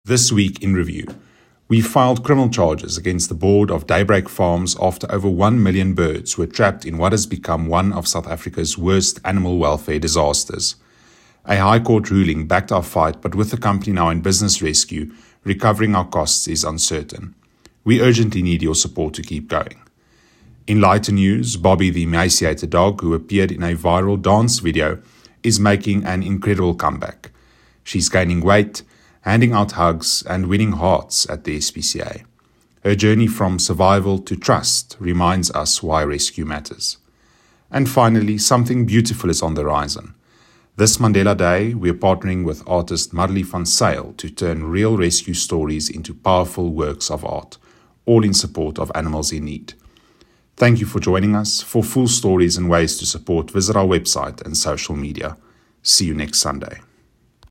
Voice-Over-2.mp3